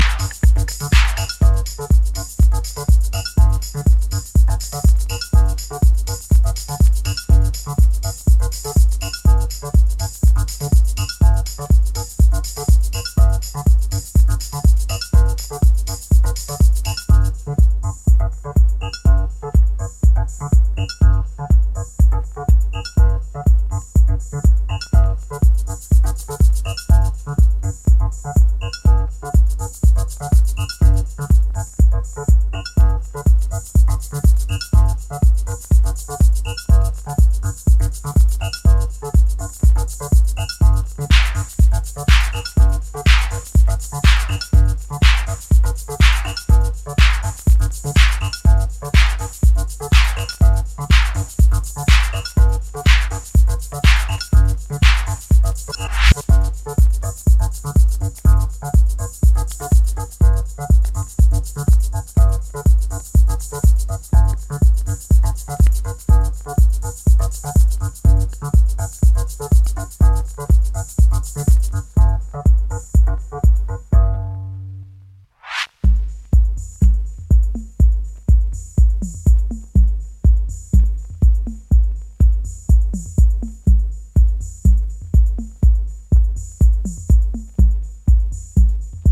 ミニマルでヒプノティックなシカゴ・ハウスのオンパレード！